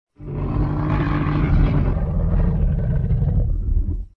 Descarga de Sonidos mp3 Gratis: animal grunido 1.